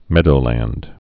(mĕdō-lănd)